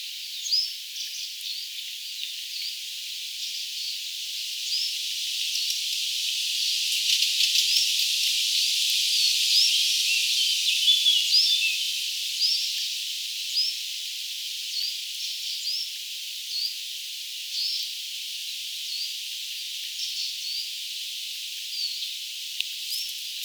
lounaisen tiltalttilinnun ääntä?
onko_tuo_sellaisen_lounaisen_tiltalttityypin_aantelya_mika_laji.mp3